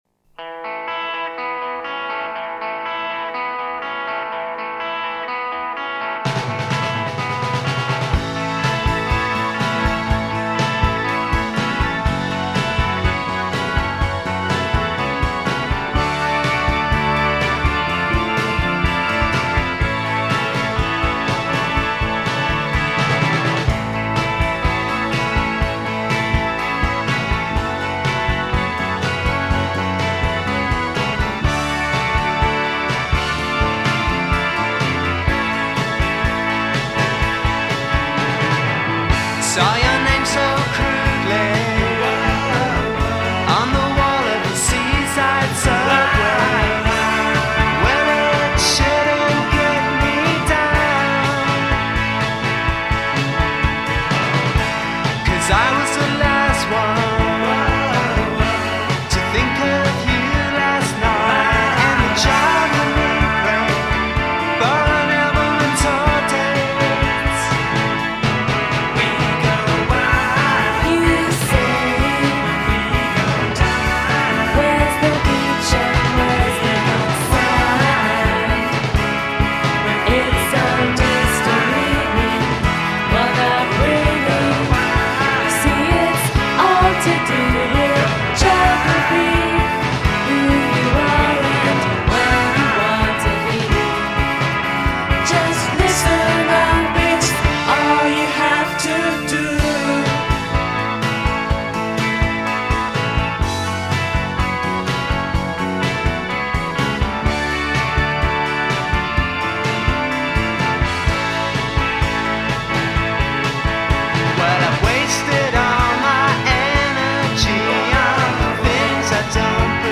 guitar
vocals
keyboards
bass
drums